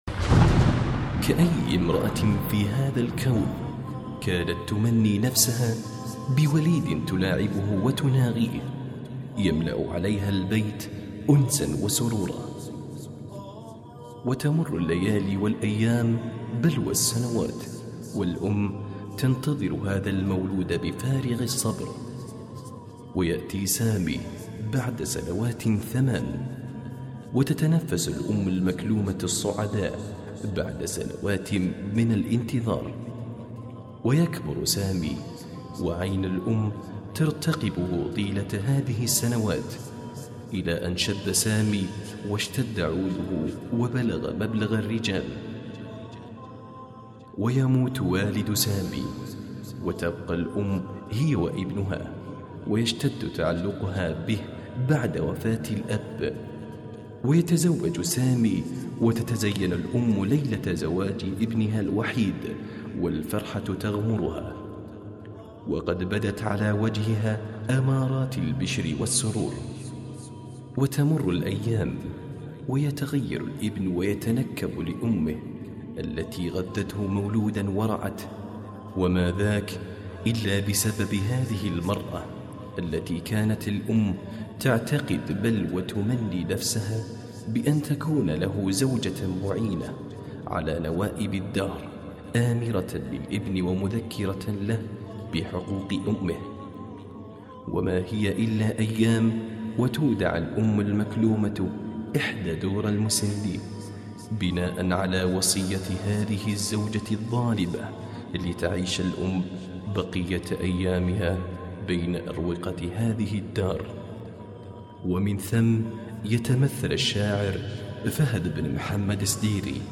أنشودهـ حزينة وكلماتها مؤثرهـ